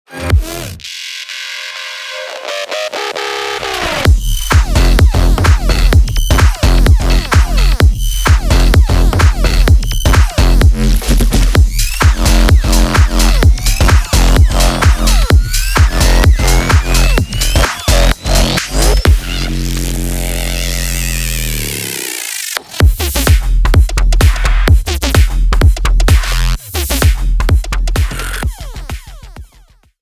Invictus for Serum (Bass House Serum Presets)